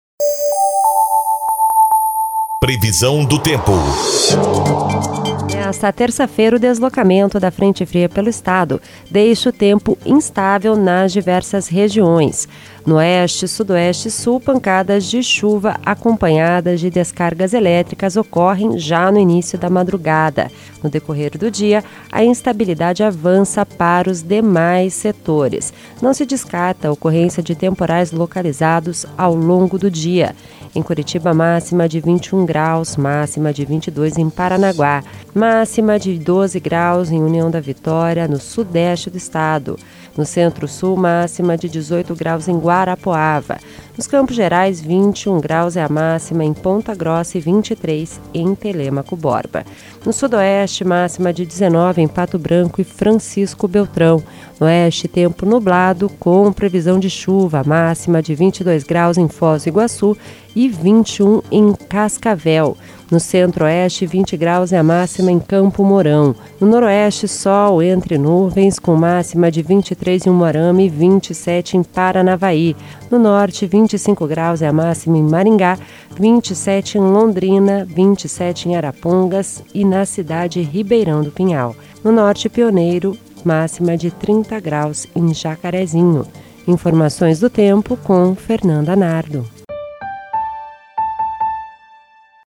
Previsão do Tempo (16/08)